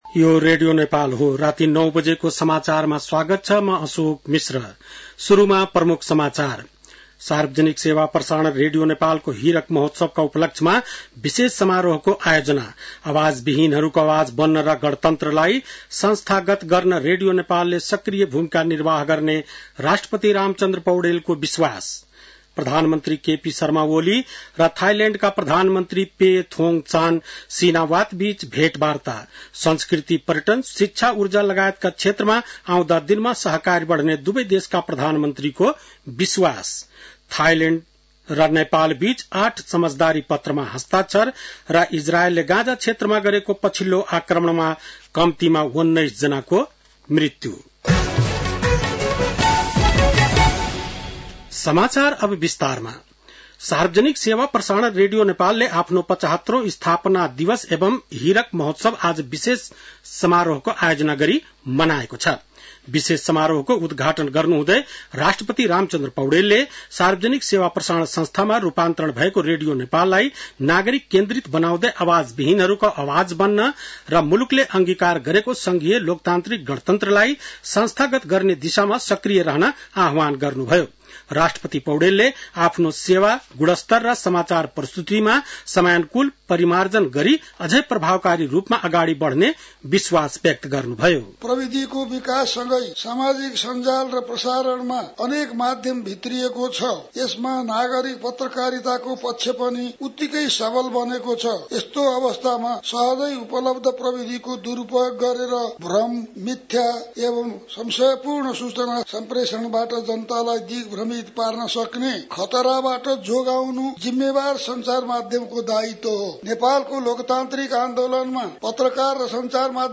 बेलुकी ९ बजेको नेपाली समाचार : २० चैत , २०८१
9-pm-nepali-news-1.mp3